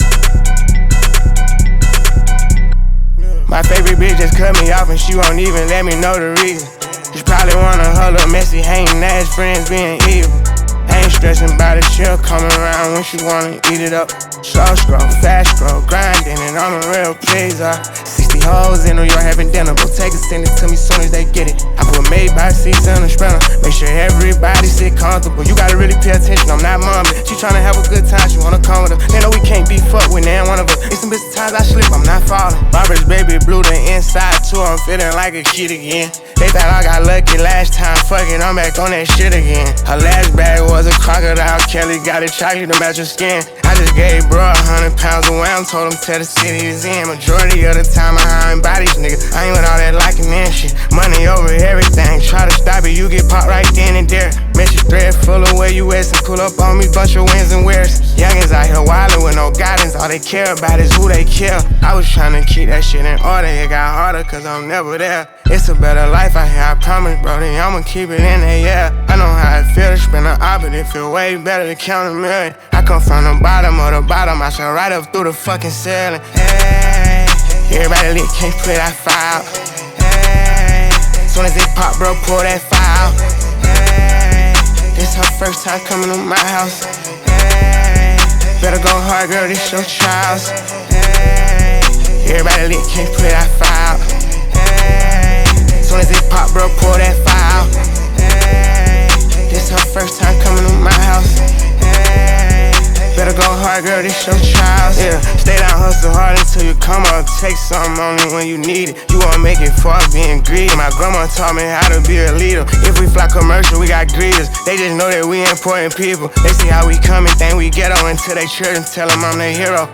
Super talented American buzzing rapper